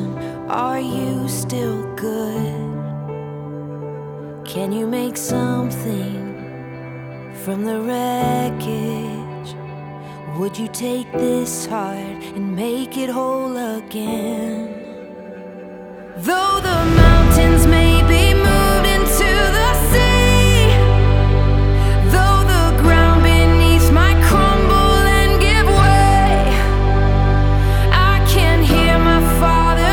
• Christian & Gospel